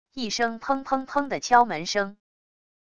一声砰砰砰的敲门声wav音频